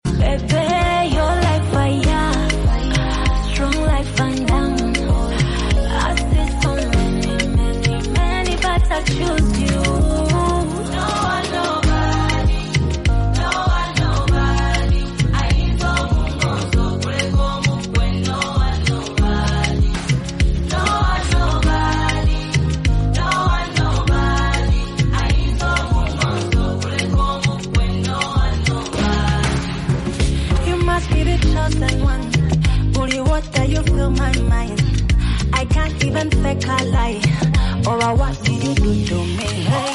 female version
heartfelt vocals and inspiring lyrics